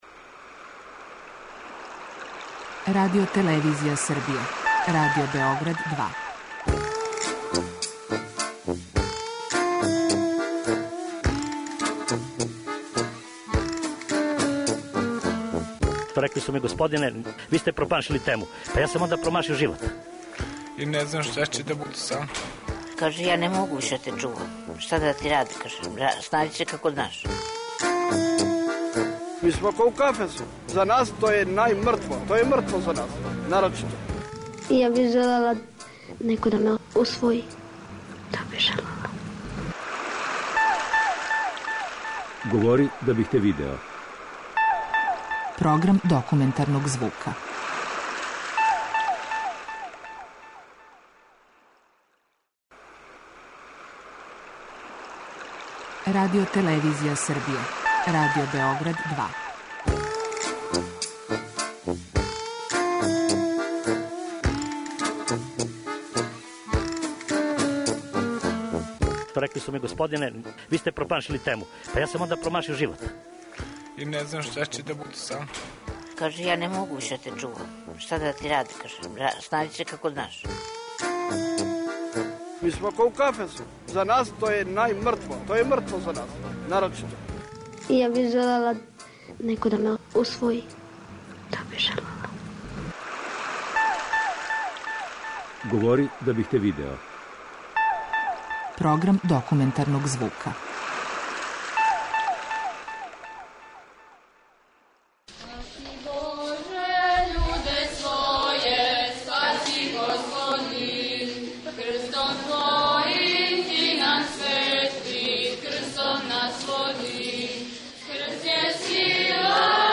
Документарни програм
Говори владика Фотије, епископ далматински.